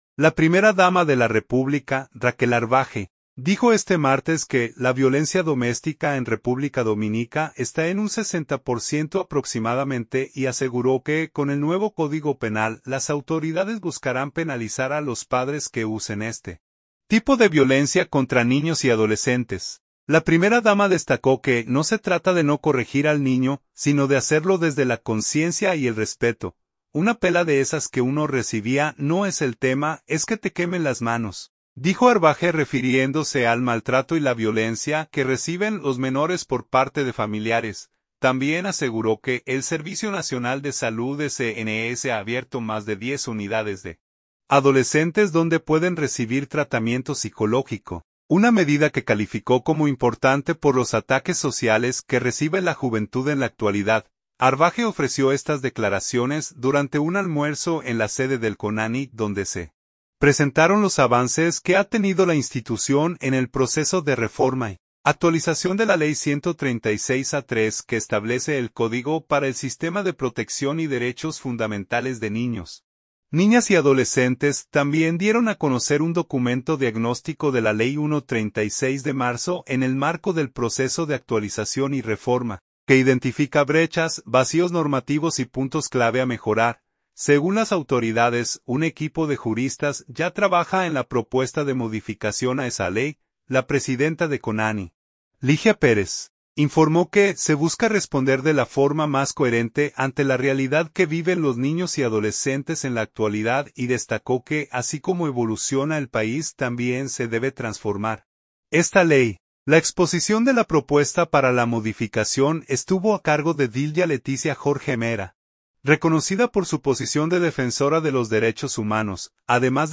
Arbaje ofreció estas declaraciones durante un almuerzo en la sede del CONANI donde se presentaron los avances que ha tenido la institución en el proceso de reforma y actualización de la ley 136-03 que establece el Código para el Sistema de Protección y Derechos fundamentales de Niños, Niñas y Adolescentes.